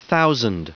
Prononciation du mot thousand en anglais (fichier audio)
Prononciation du mot : thousand